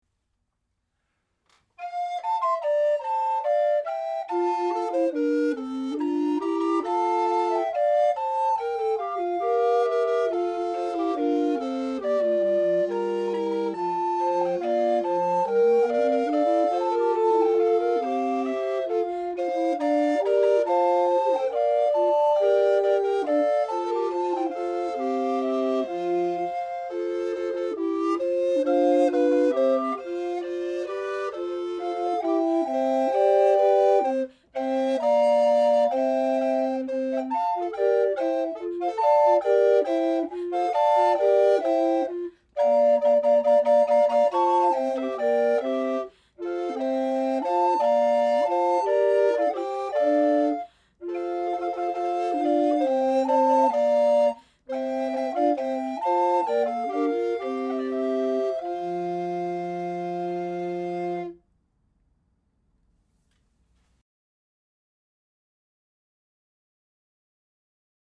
Music from the 15th and 16th centuries
recorders
at the Loring-Greenough House, Jamaica Plain